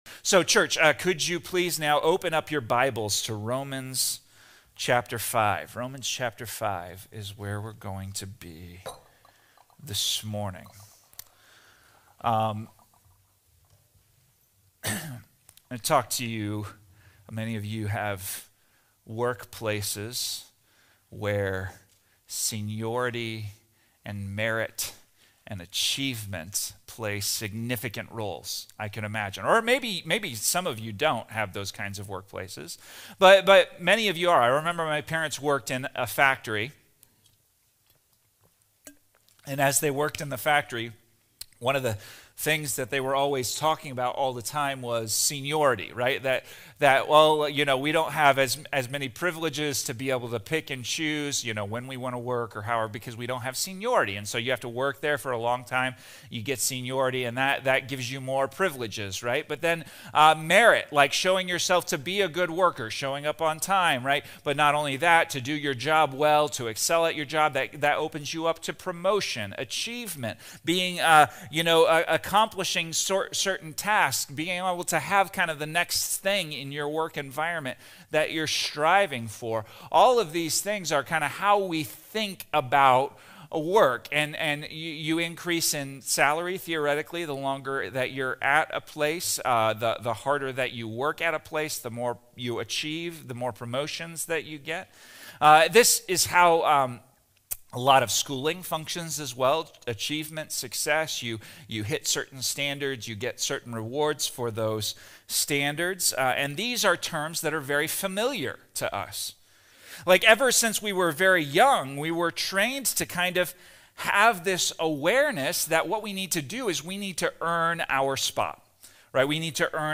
This sermon dives into Romans 5, showing how grace isn’t just kindness; it’s radical, life-changing, and completely undeserved. While Adam’s sin brought destruction and death, Jesus’ sacrifice brings restoration, hope, and righteousness to anyone who believes.